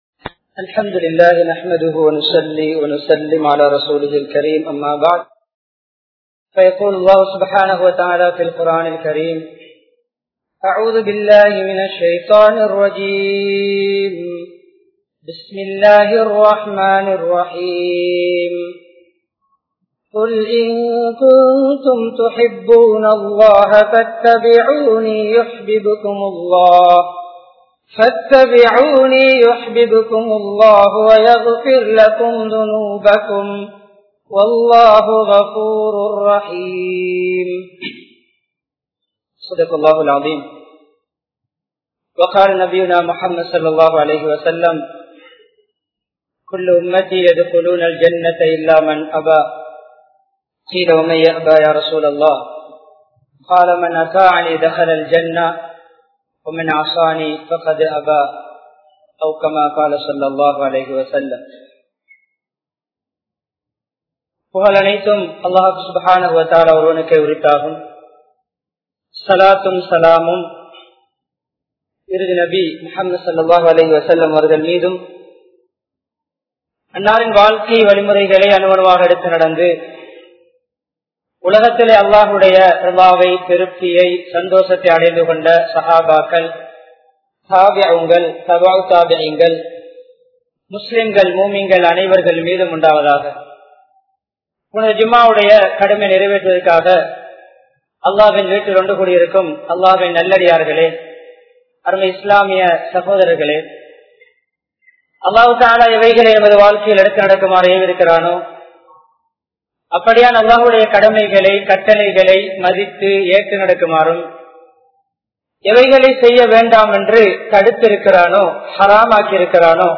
Nabiyavarhalai Unmaiyaaha Neasippoam (நபியவர்களை உண்மையாக நேசிப்போம்) | Audio Bayans | All Ceylon Muslim Youth Community | Addalaichenai
Colombo 12, Aluthkade, Muhiyadeen Jumua Masjidh